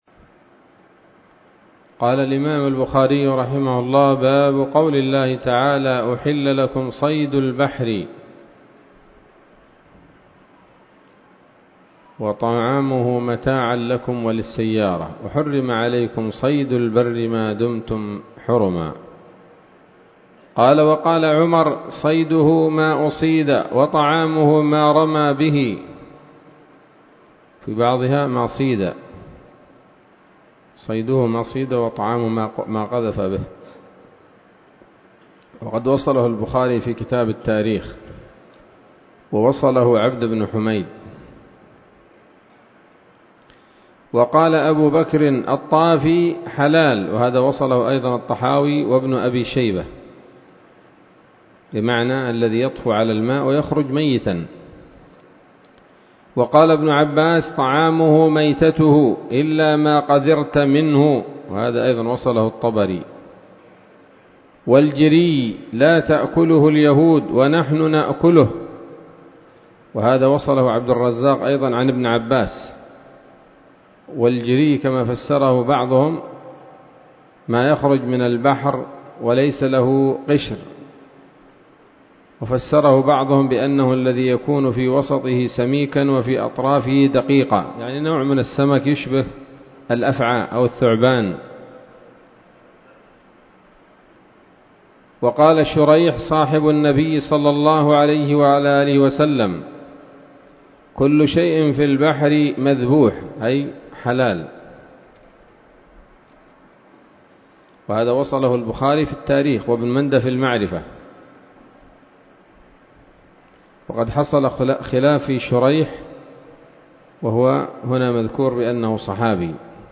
الدرس العاشر من كتاب الذبائح والصيد من صحيح الإمام البخاري